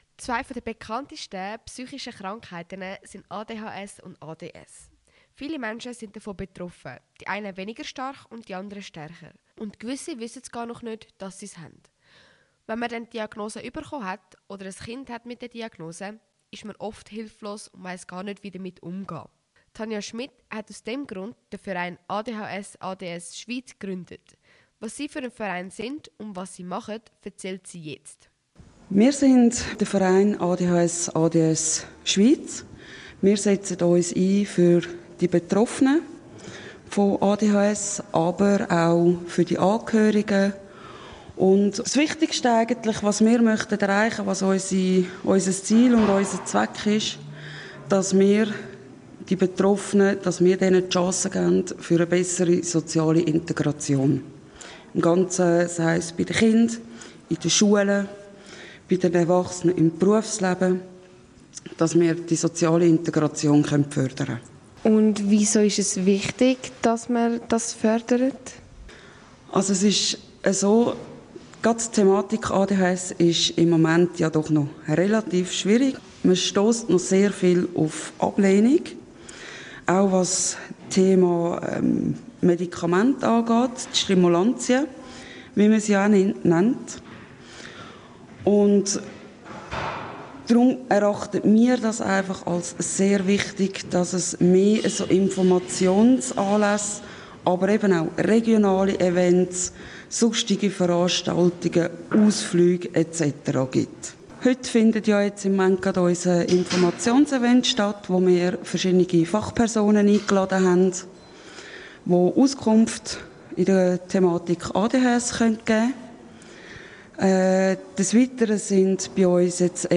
Ausserdem erfährst du, wie der Verein dazu beiträgt, das Bewusstsein für ADHS zu stärken, Vorurteile abzubauen und Wege aufzuzeigen. Zudem sprechen wir mit Experten und Expertinnen verschiedener Angebote, die Betroffenen und ihren Familien praktische Unterstützung bieten.